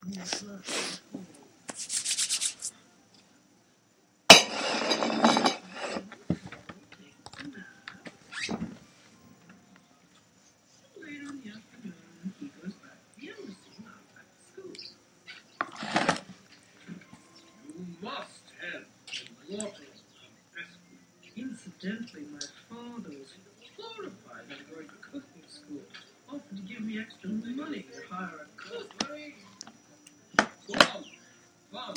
Field Recording Number Twelve
Location: Chelmsford, MA–my house
Sounds Heard: television, , hands clapping, cabinet drawers opening and closing, sniffles.